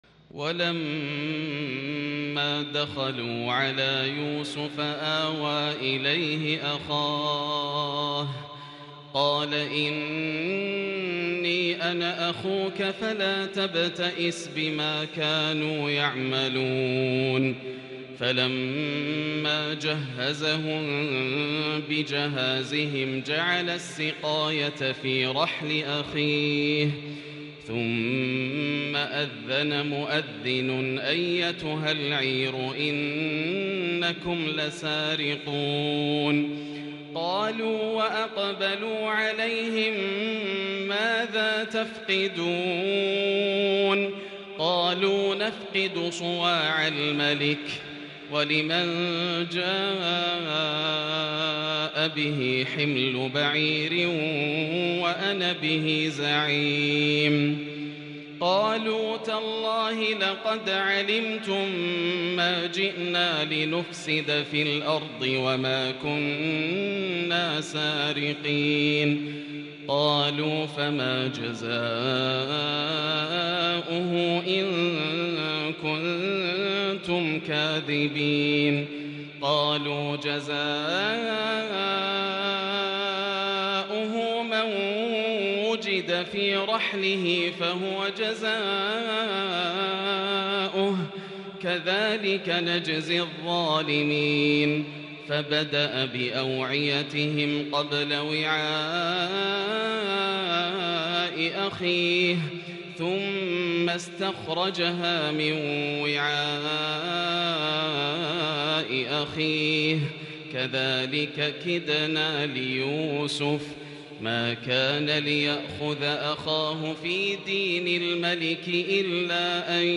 صلاة العشاء ١٤٤٢/٤/١هـ - من سورة يُوسف  Isha prayer surah Yusuf 16/11/2020 > 1442 🕋 > الفروض - تلاوات الحرمين